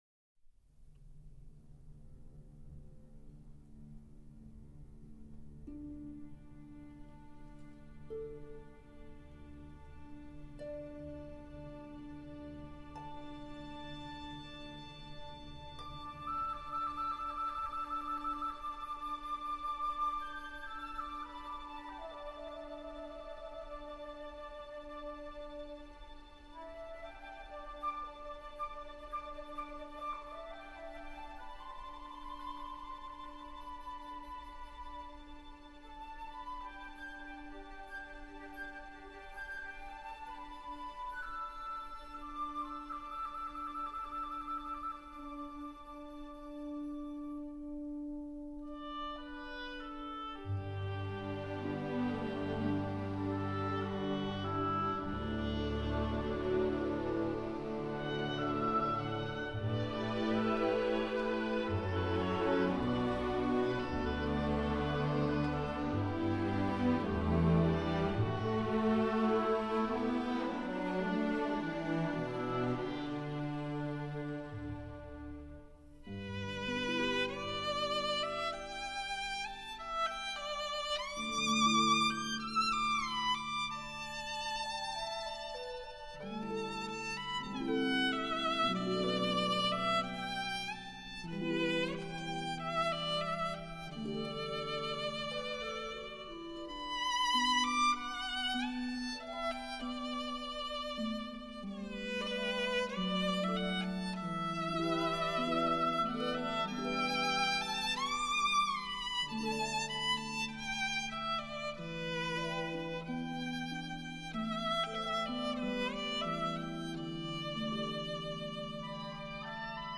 Violin Concerto